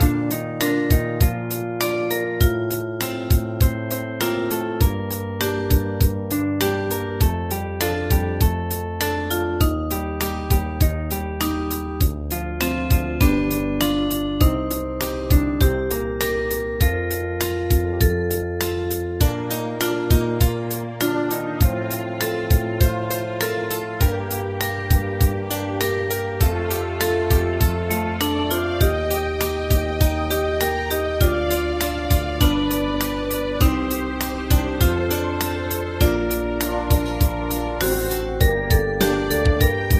Ensemble musical score and practice for data.
Tags: Japanese , Kayokyoku Enka .